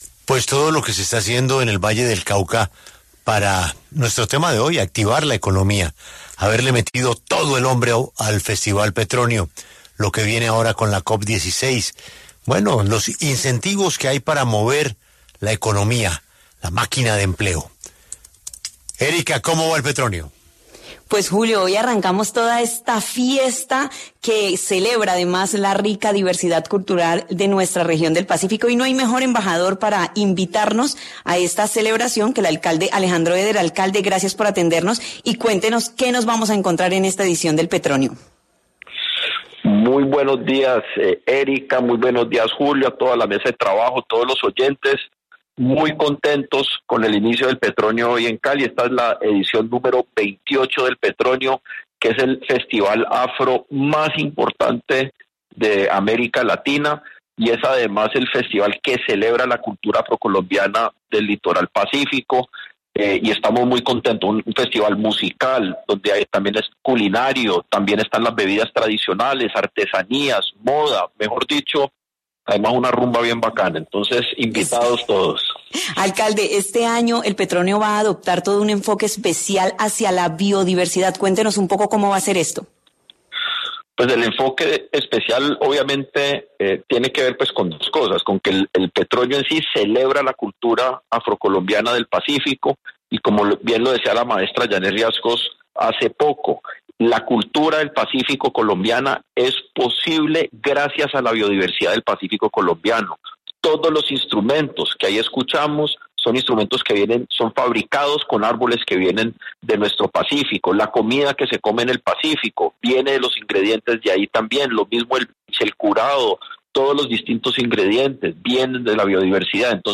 Alejandro Éder, alcalde de Cali, conversó con La W sobre la realización de la edición #28 del Festival Petronio Álvarez, una fiesta para exaltar la riqueza y la diversidad de la música afrocolombiana.